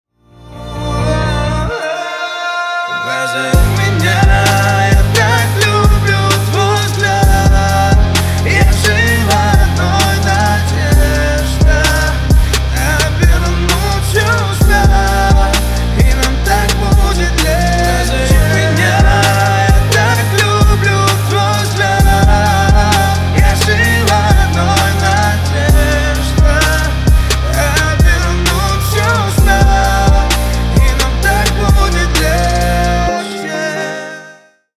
Поп Музыка
грустные